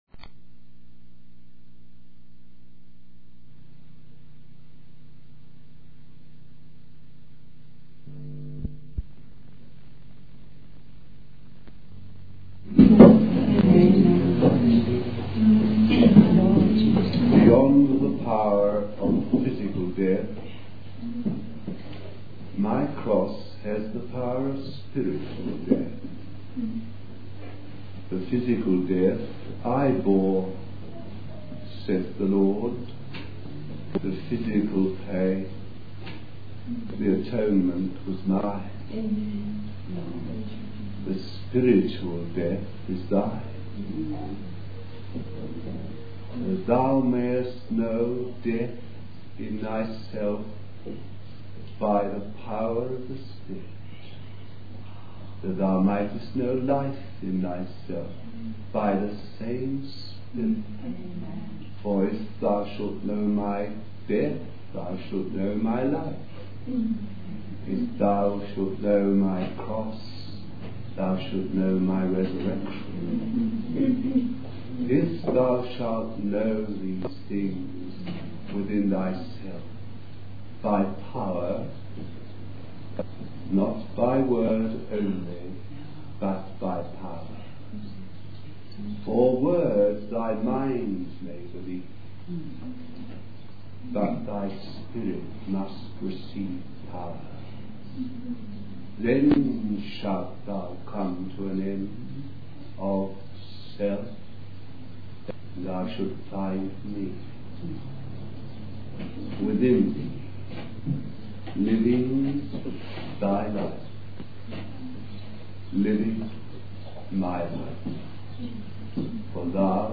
He also mentions Moses preparing the people to enter the promised land and possess their possessions. The sermon highlights the need to read the Bible properly and understand the identification of the speaker with God's promises.